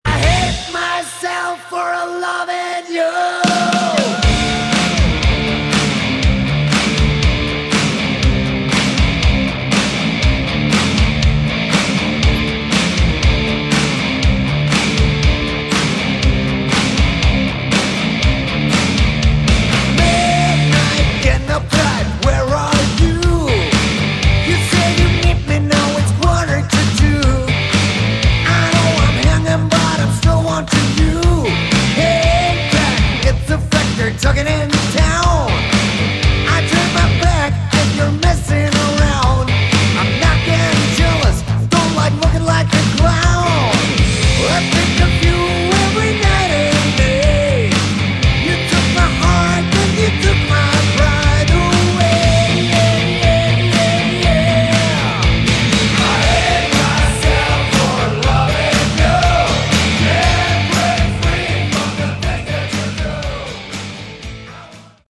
Category: Hard Rock
vocals
guitar
drums
bass